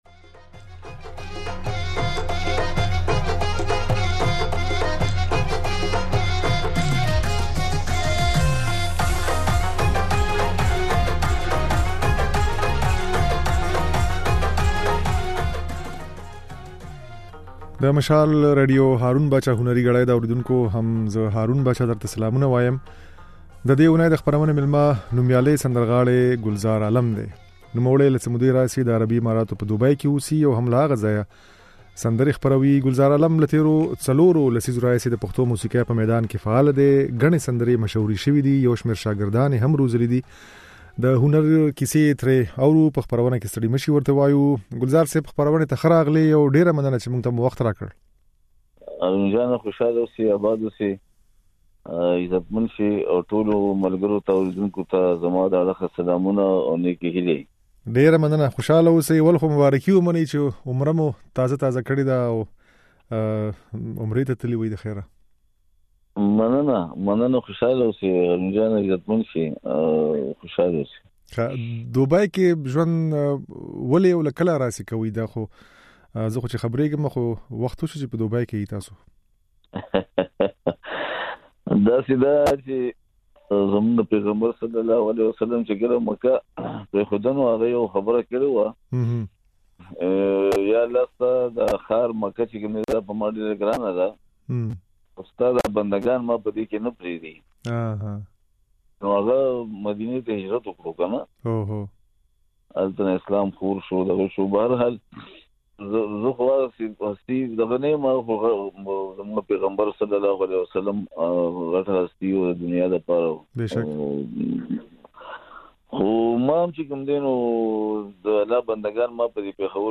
د دې اونۍ د "هارون باچا هنري ګړۍ" خپرونې مېلمه نوميالی سندرغاړی ګلزار عالم دی.
د ګلزار عالم دا خبرې او ځينې سندرې يې په خپرونه کې اورېدای شئ.